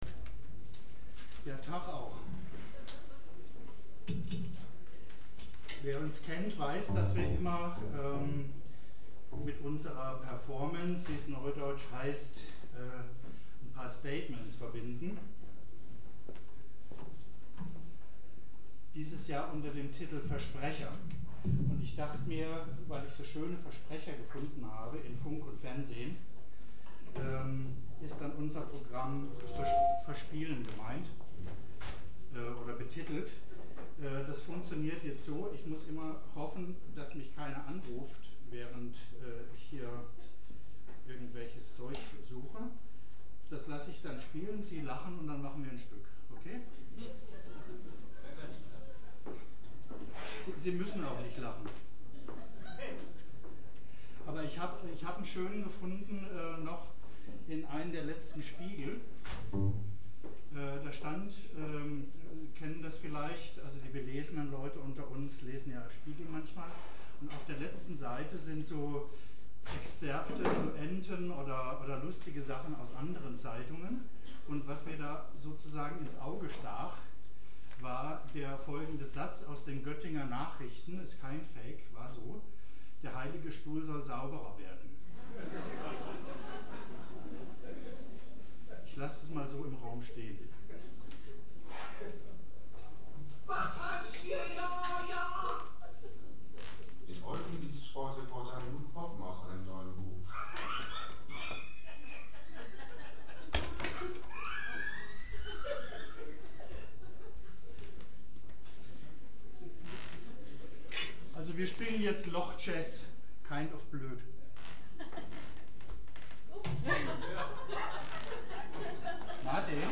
2 01 Ansage [2:39]
01 - Ansage.mp3